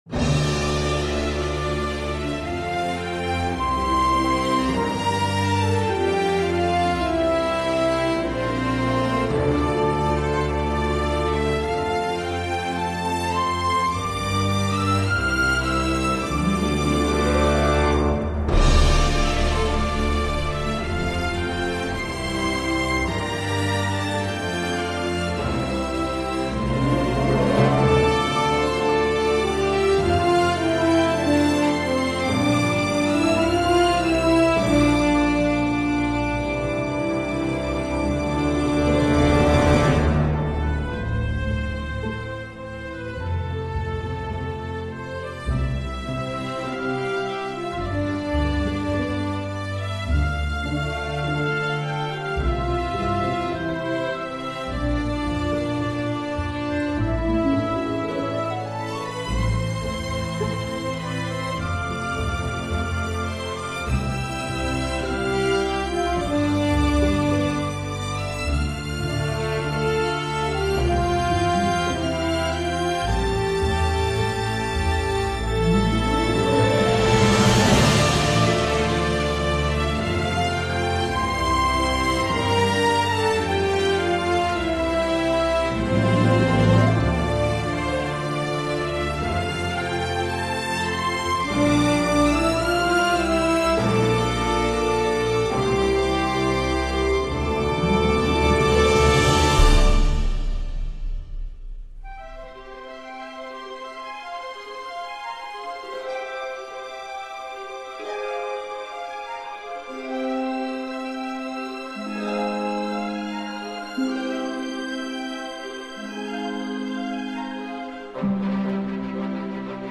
d’une ampleur modeste (ça sonne parfois comme un mock-up
Bref, c’est old school, et moi ça me va.